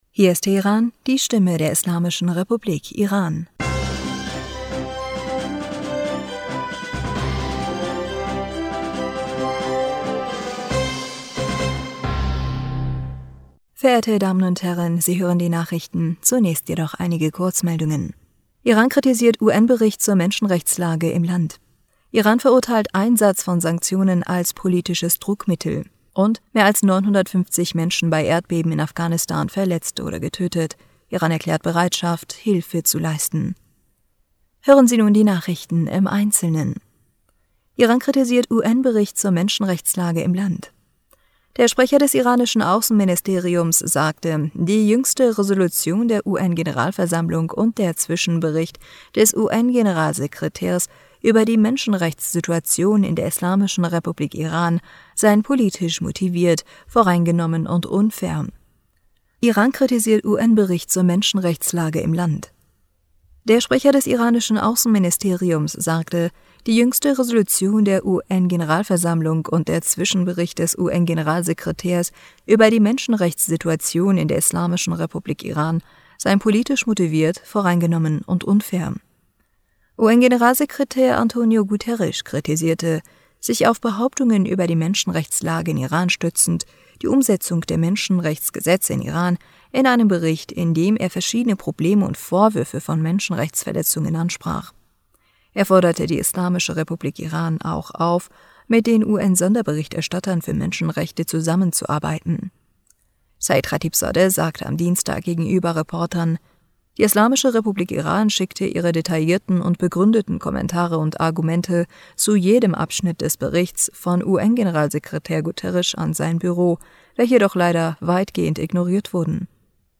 Nachrichten vom 22. Juni 2022
Die Nachrichten vom Mittwoch dem 22. Juni 2022